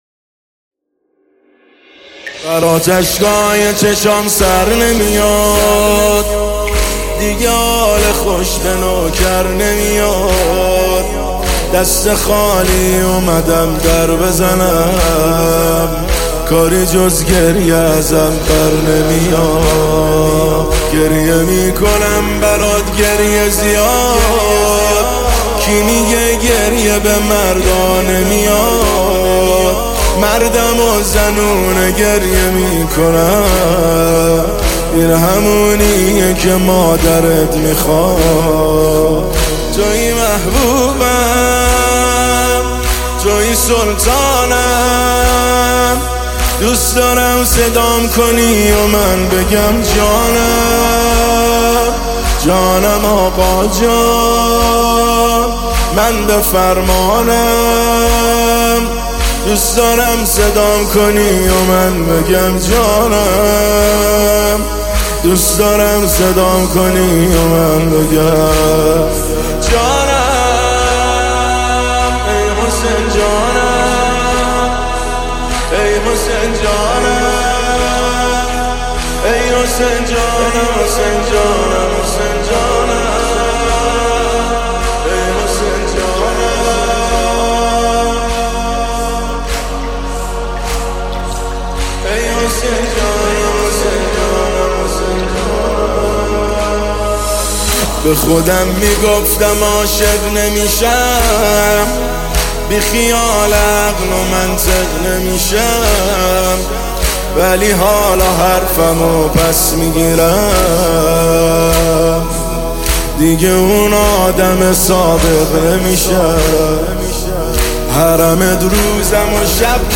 مداحی امام حسین مداحی ماه محرم